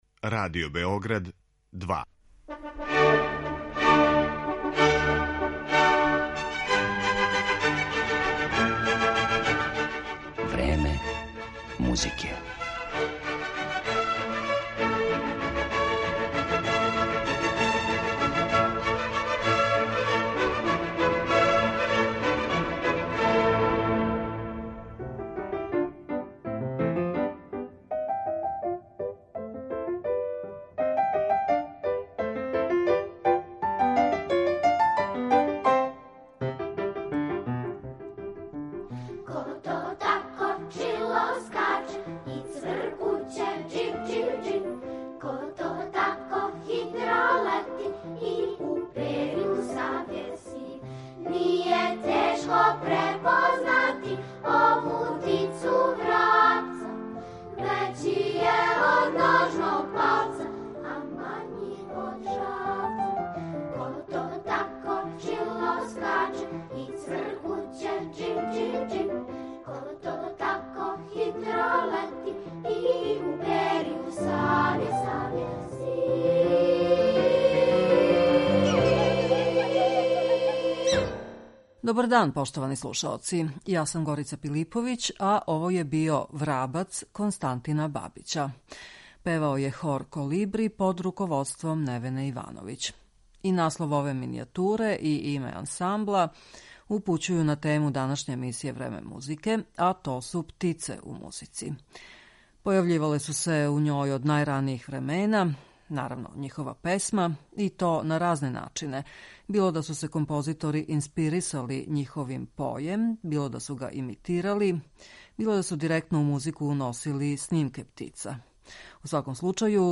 мадригалу из XVI века